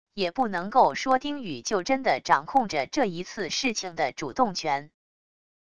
也不能够说丁羽就真的掌控着这一次事情的主动权wav音频生成系统WAV Audio Player